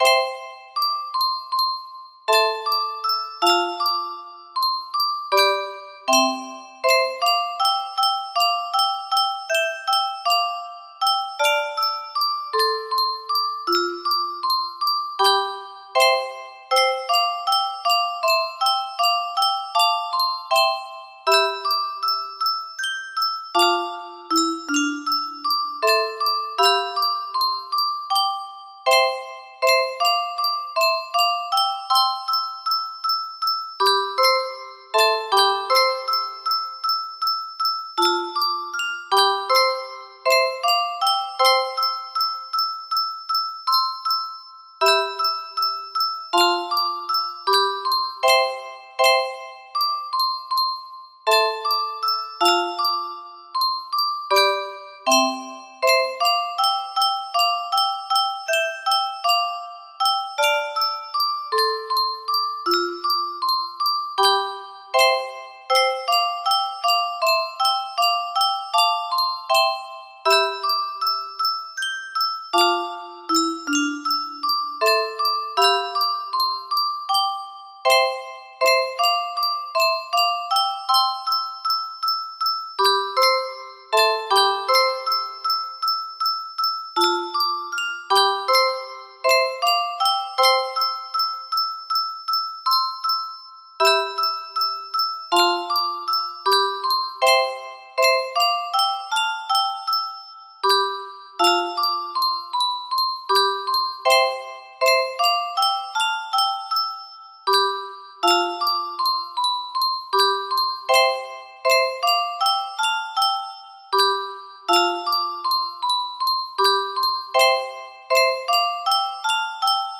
Antiphona ad Communionem music box melody